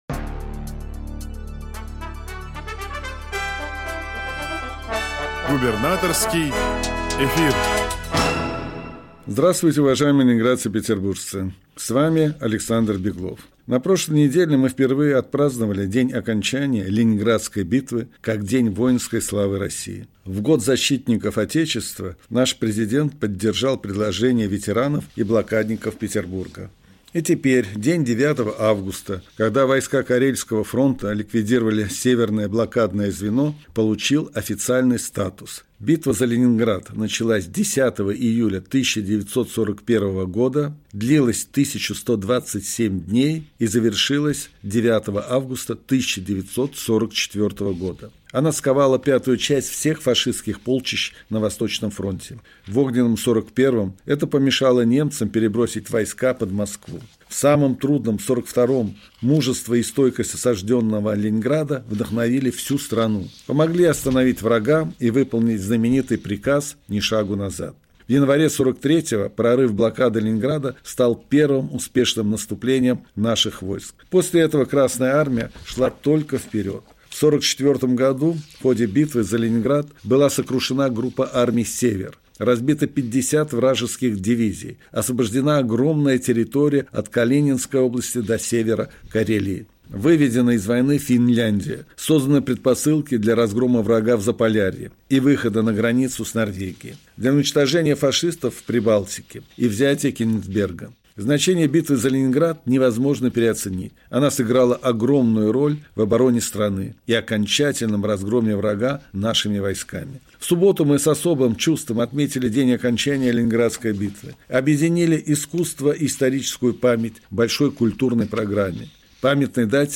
Радиообращение – 11 августа 2025 года